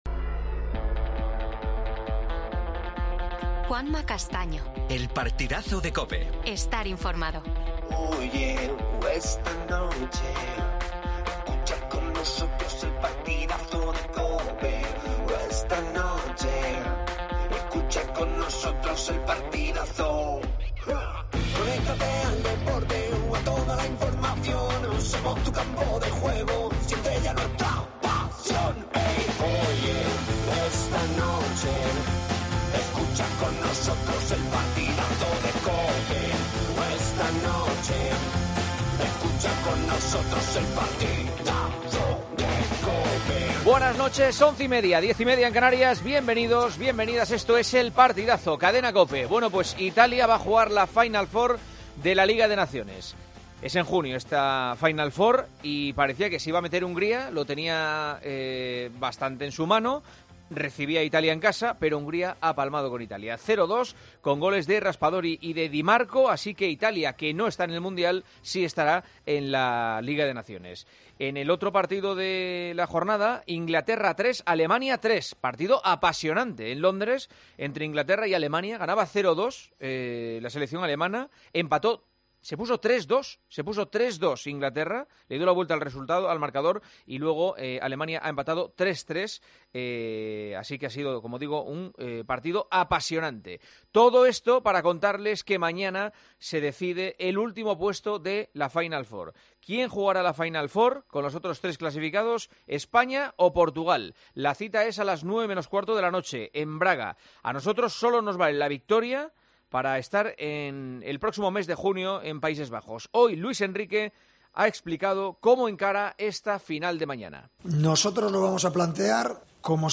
AUDIO: Noticias del día. Entrevista a Sergio Gómez, jugador del City y de la selección sub 21.